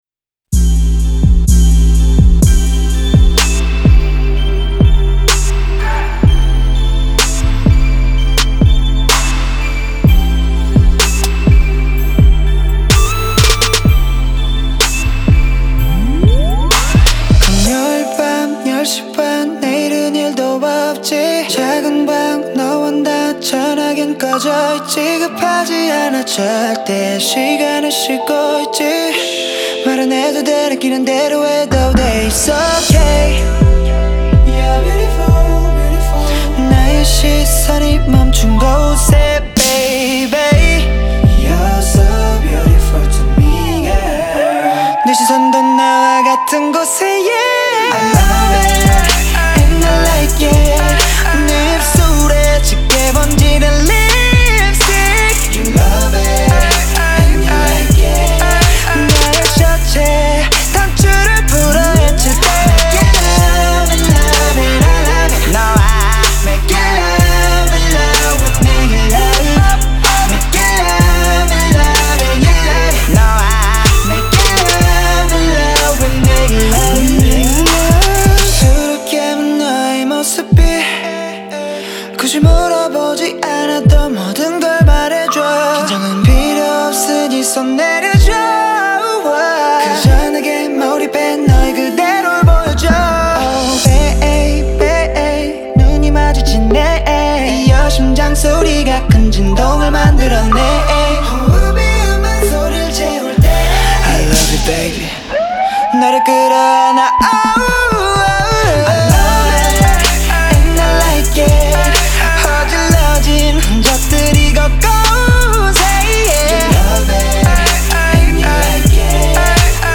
smooth R&B track